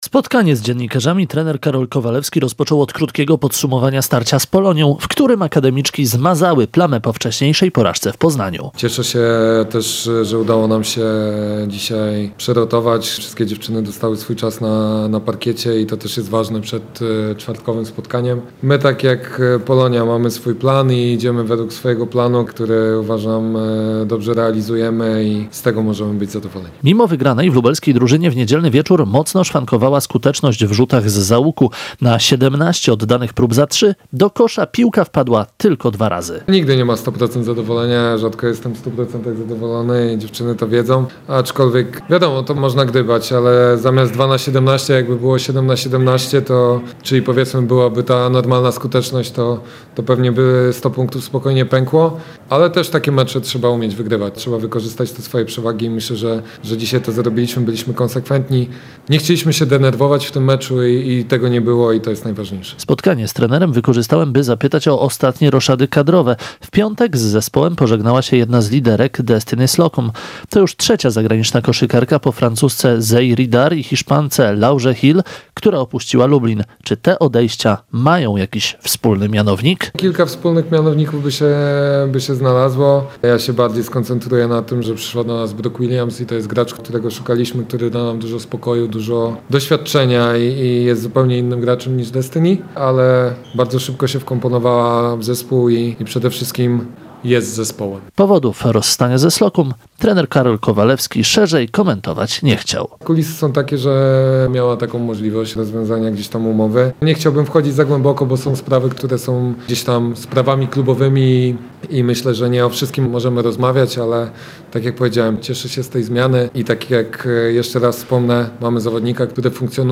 Pomeczowa konferencja